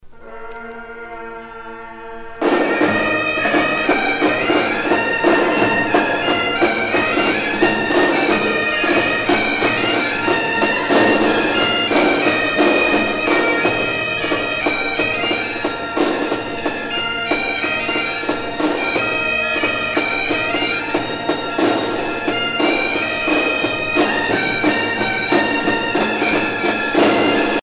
Défilé et triomphe des sonneurs aux Fêtes de Cornouaille
Pièce musicale éditée